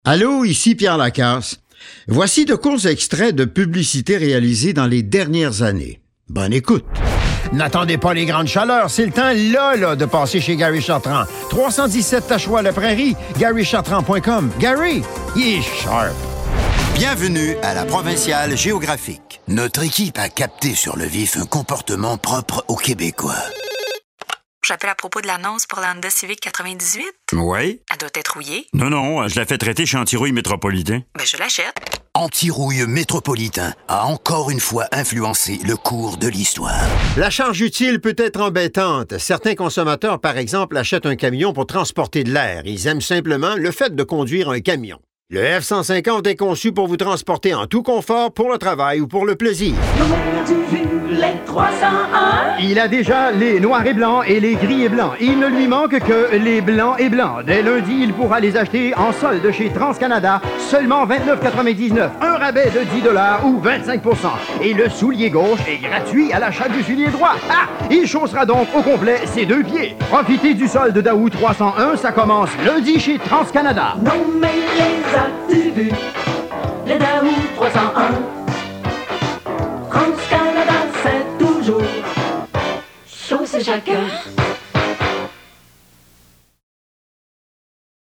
DÉMO(S) VOIX